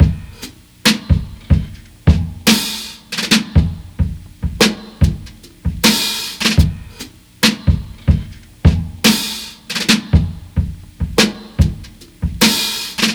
• 73 Bpm Drum Loop Sample C# Key.wav
Free drum loop sample - kick tuned to the C# note. Loudest frequency: 2152Hz
73-bpm-drum-loop-sample-c-sharp-key-c1K.wav